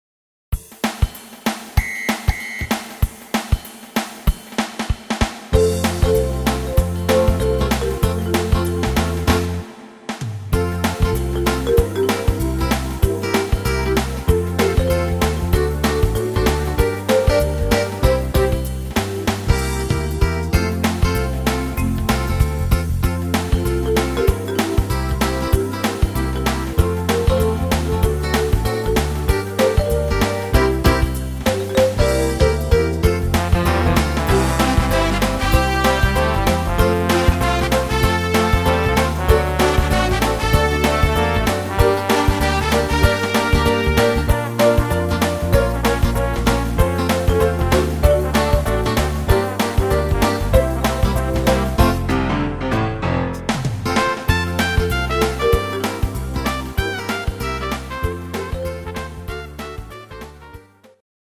Trompete